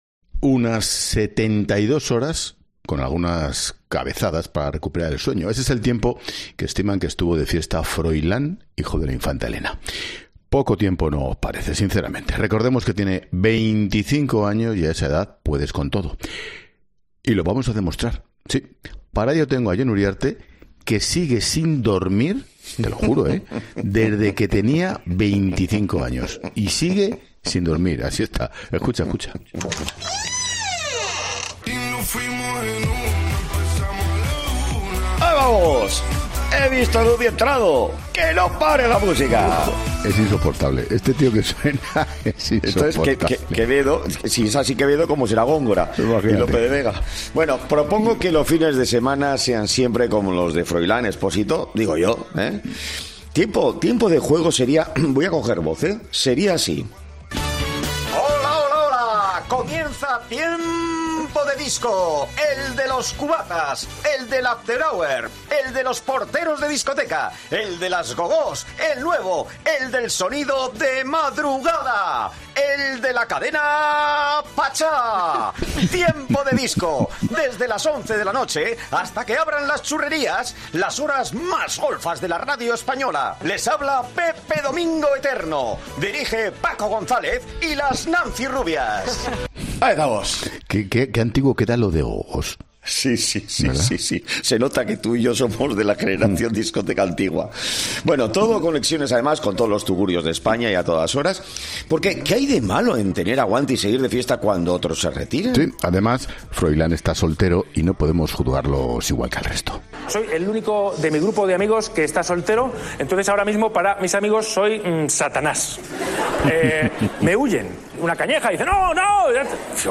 Así, el periodista vasco ha propuesto que los fines de semana sean “siempre como los de Froilán, ¿qué hay de malo en tener aguante y seguir de fiesta cuando otros se retiran?”, bromeaba antes de realizar su peculiar versión del arranque de 'Tiempo de Juego', pero versión fiesta.
“No deja de ser hipócrita que critiquemos a los jóvenes por fiesteros cuando, quien más quien menos, ha tenido una fiesta como la de María Jiménez”, bromea el director de La Linterna que terminaba su alegato en euskera, gritando un “Froilán askatu”, que significa “Froilán libertad”.